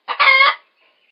PixelPerfectionCE/assets/minecraft/sounds/mob/chicken/say2.ogg at mc116